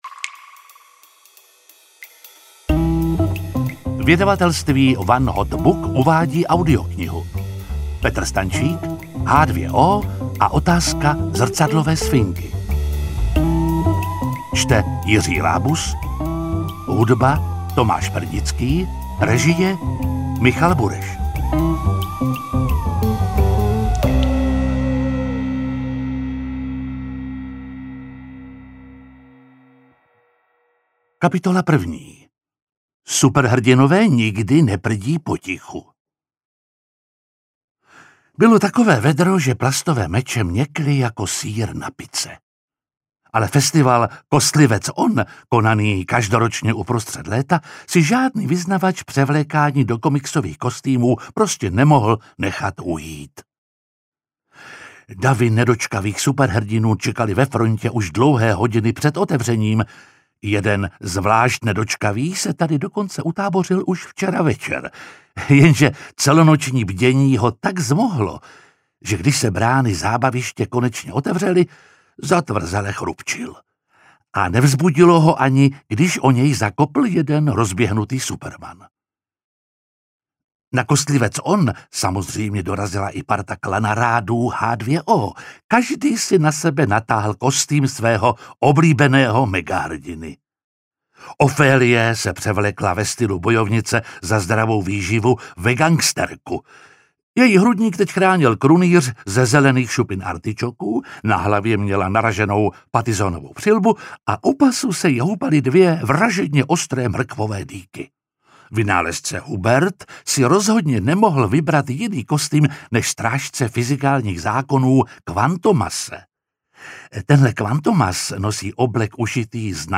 Interpret:  Jiří Lábus